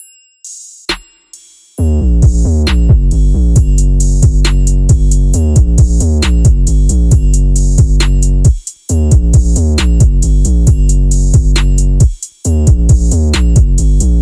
描述：黄石国家公园，泥浆火山区，冒泡的灰熊火山
Tag: 鼓泡 喷发 间歇泉 泥罐